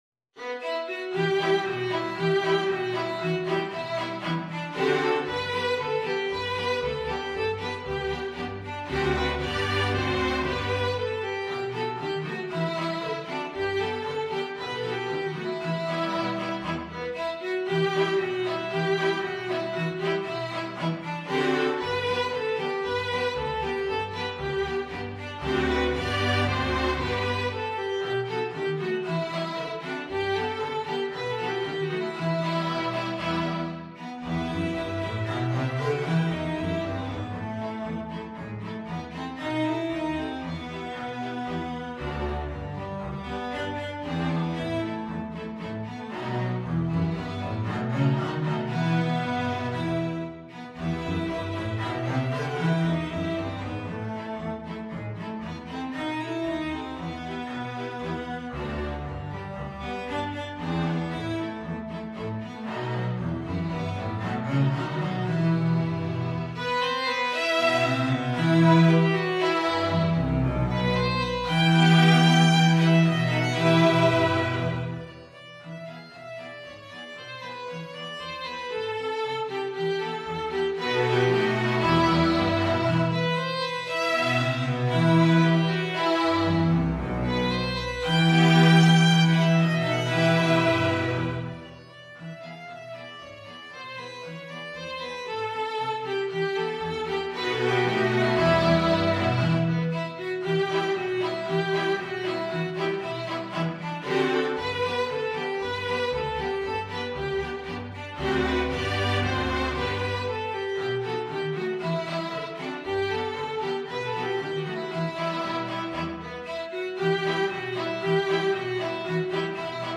Violin 1Violin 2ViolaCelloDouble Bass
Allegro =c.116 (View more music marked Allegro)
2/4 (View more 2/4 Music)
Traditional (View more Traditional String Ensemble Music)
world (View more world String Ensemble Music)